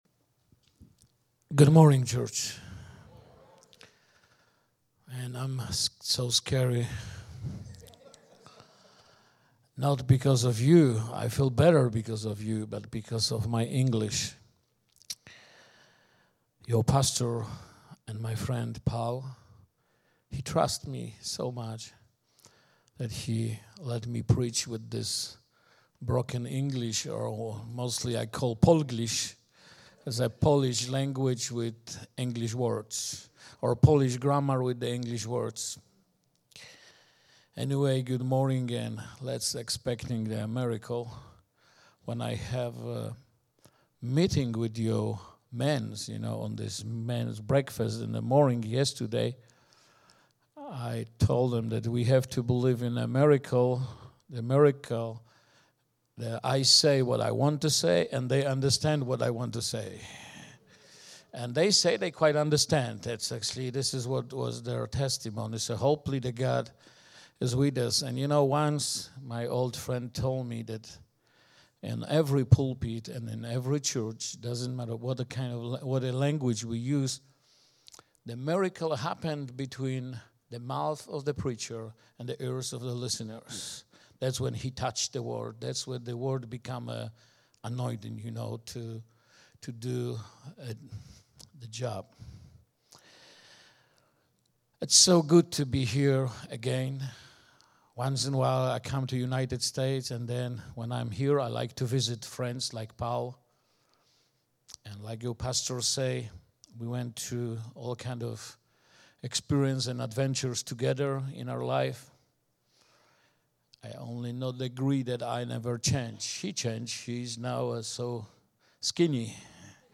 Series: Stand Alone Sermon